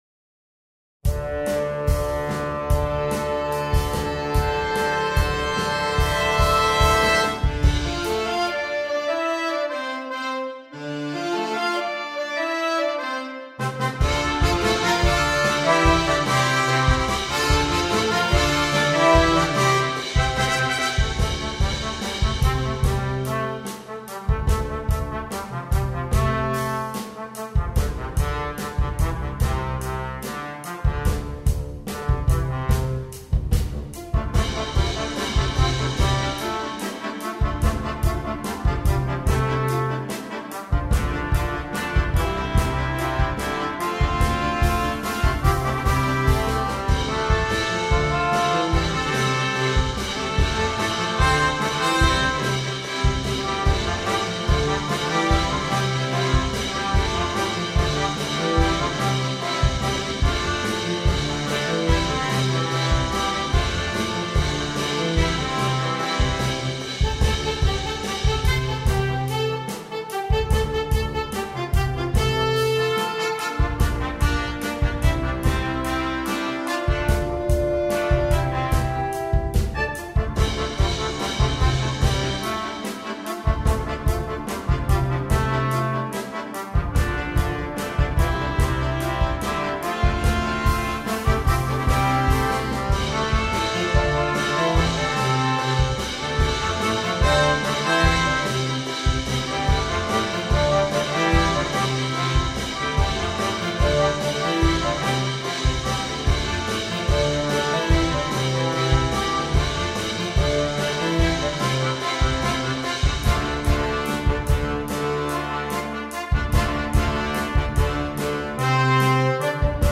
для брасс-бэнда